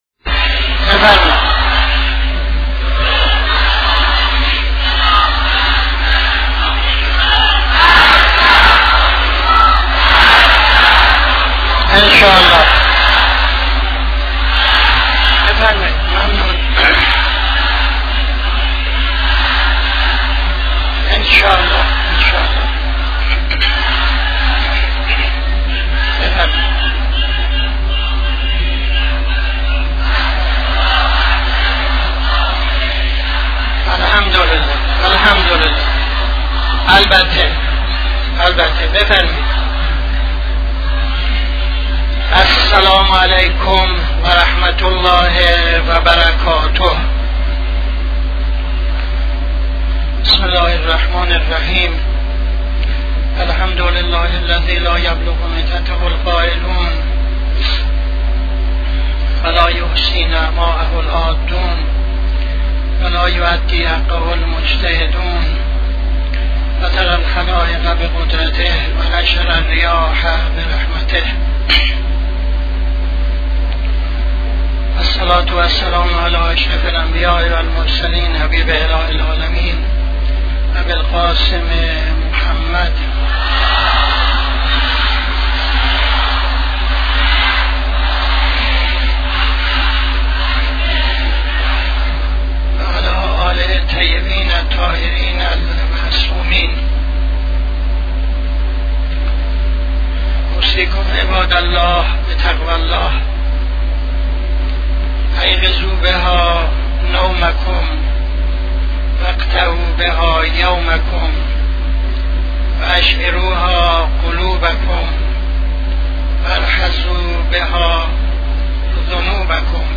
خطبه اول نماز جمعه 28-09-76